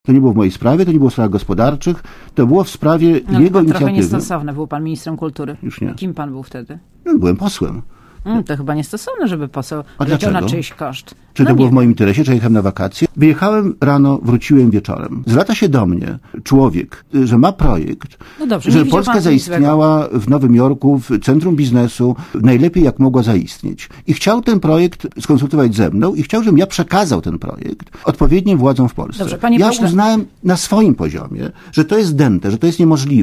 Źródło: PAP Komentarz audio W związku ze sprawą Andrzej Celiński zrezygnował z zadawania pytań w czasie sobotniego przesłuchania Dochnala przed sejmową komisją śledczą.